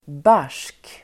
Uttal: [bar_s:k]